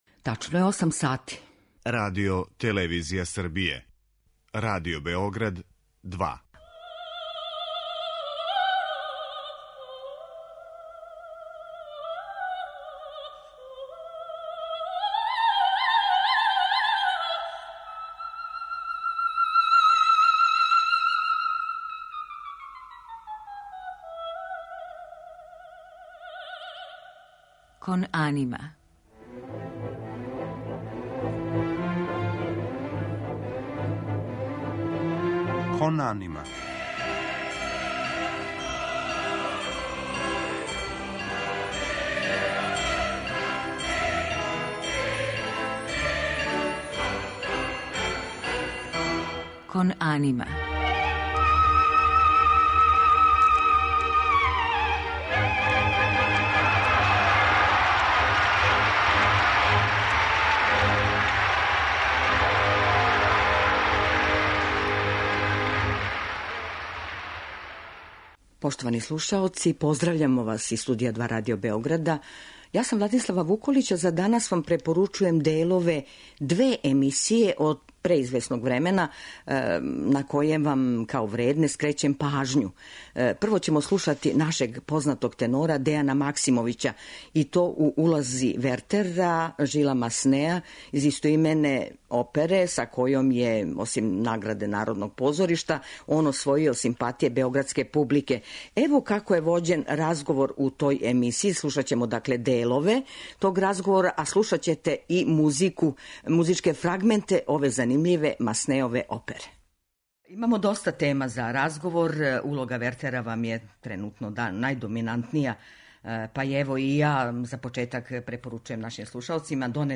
У другом делу емисије, наша прослављена уметница Радмила Бакочевић пренеће своја сећања са оперских наступа у Београду и на великим светским сценама.